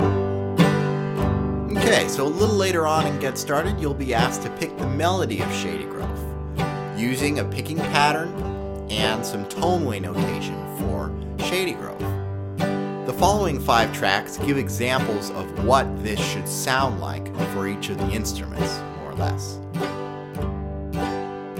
Rhythm: Bass